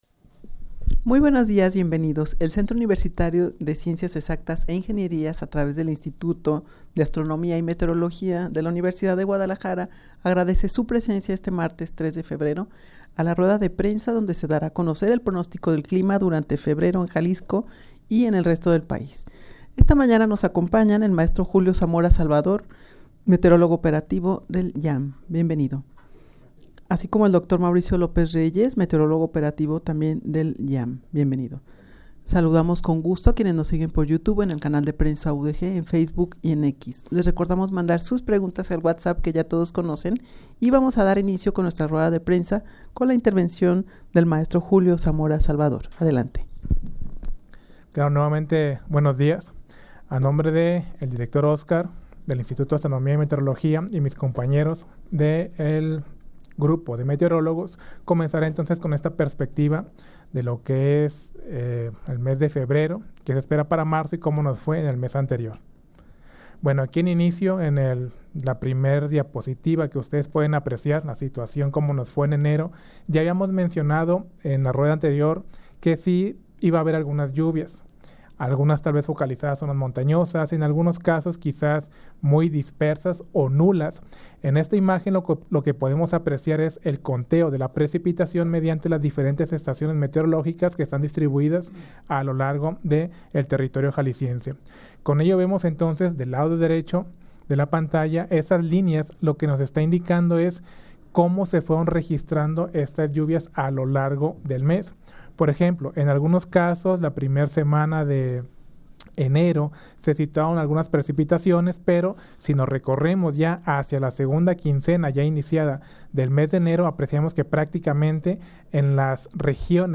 Audio de la Rueda de Prensa
rueda-de-prensa-para-dar-a-conocer-el-pronostico-del-clima-durante-febrero-en-jalisco-y-en-el-resto-del-pais.mp3